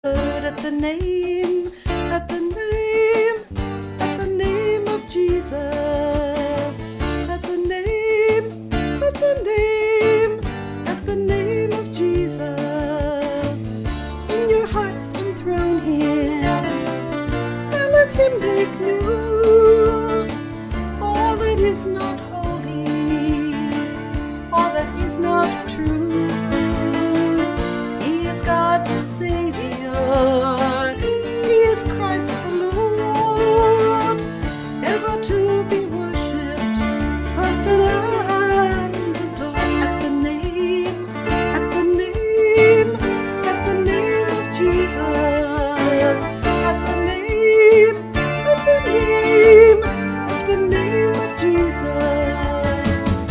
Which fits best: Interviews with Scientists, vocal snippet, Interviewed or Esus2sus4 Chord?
vocal snippet